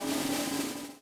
-BUZZ ROL5-L.wav